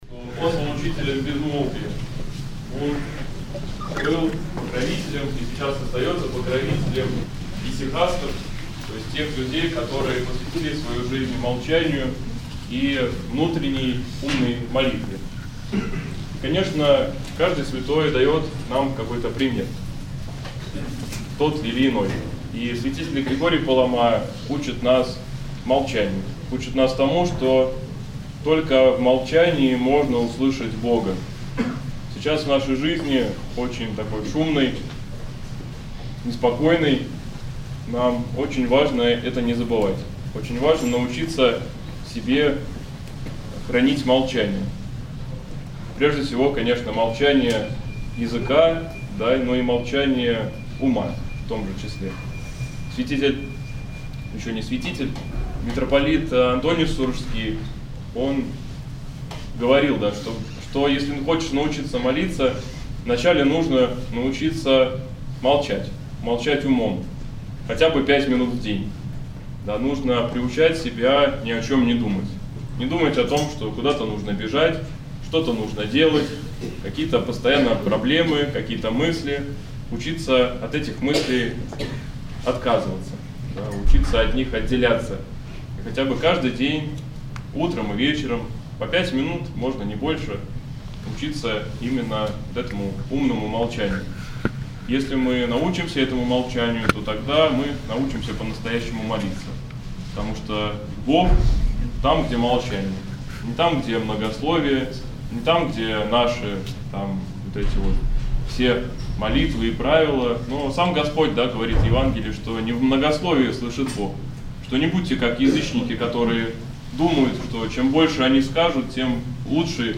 ранняя Литургия